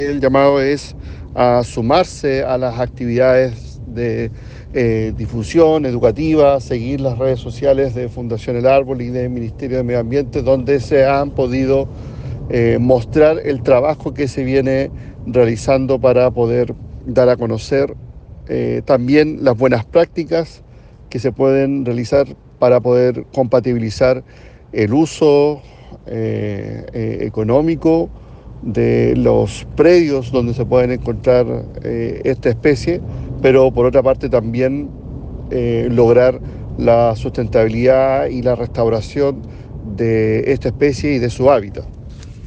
cuna-3-seremi-oscar-reicher.mp3